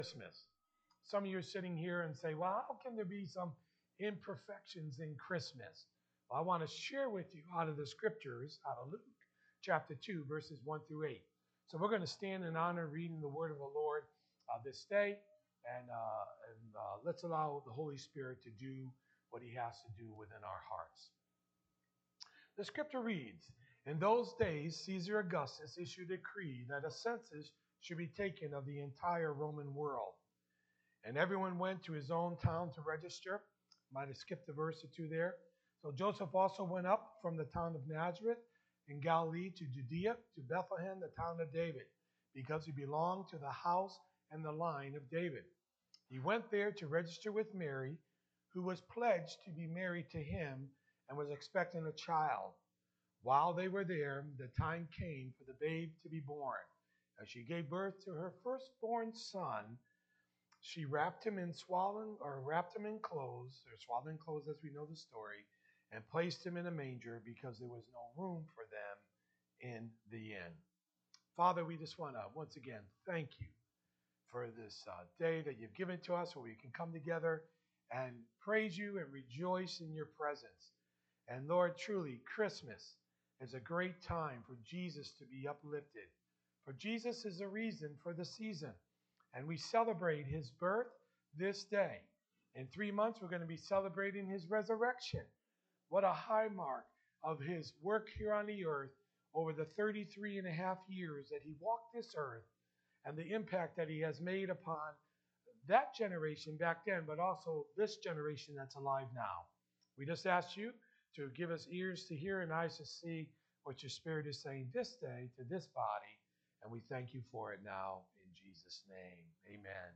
Sermons | Oneonta Assembly of God